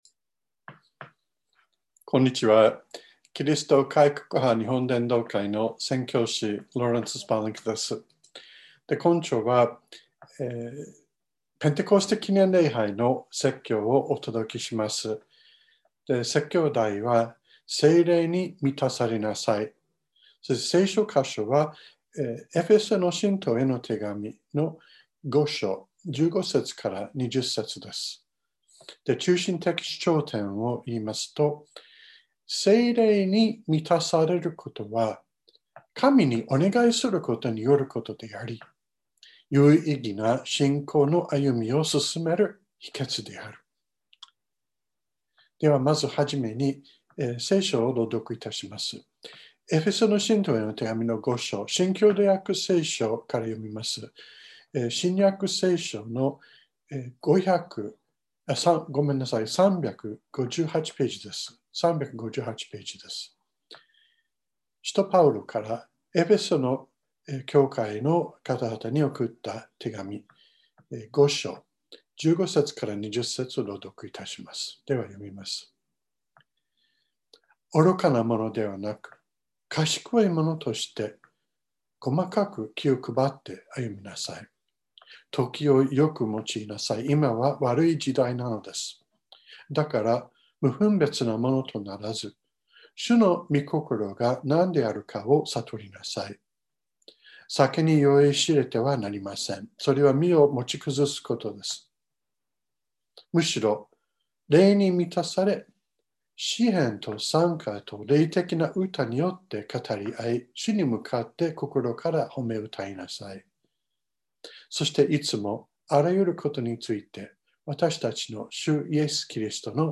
2021年05月23日朝の礼拝「聖霊に満たされなさい」川越教会
説教アーカイブ。